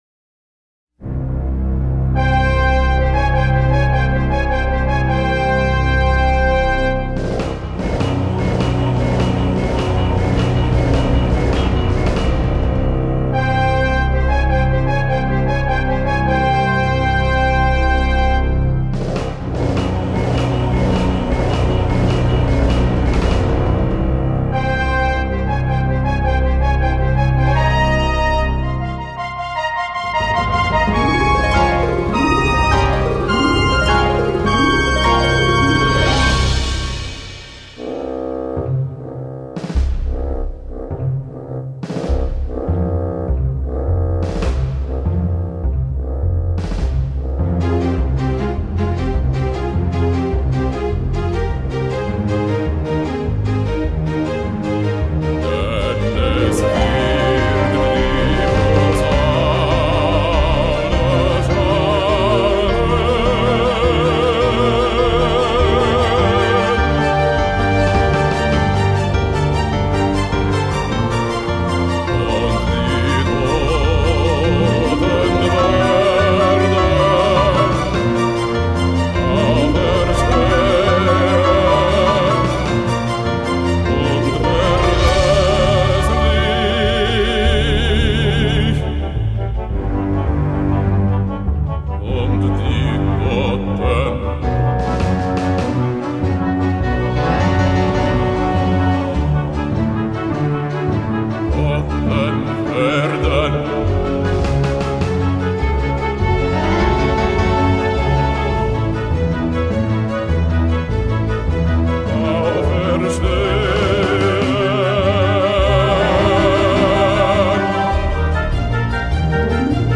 tällä kertaa oopperamusiikilla.